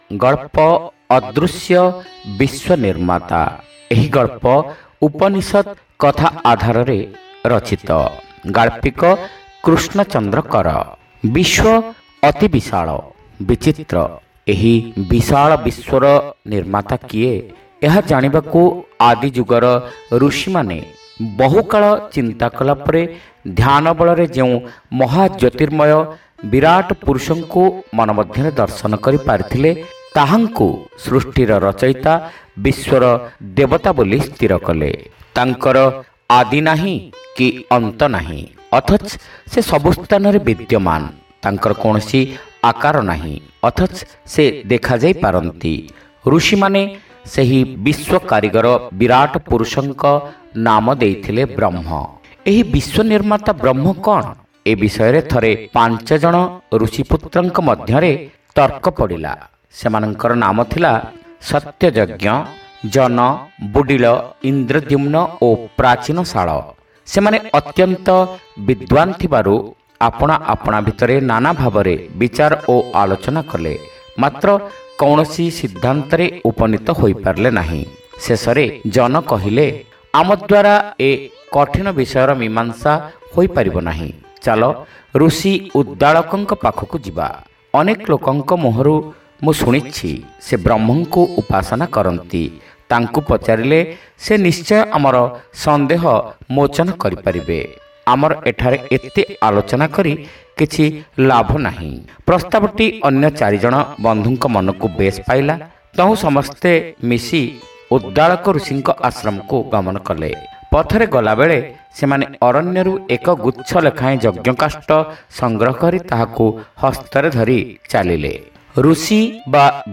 ଶ୍ରାବ୍ୟ ଗଳ୍ପ : ଅଦୃଶ୍ୟ ବିଶ୍ୱ ନିର୍ମାତା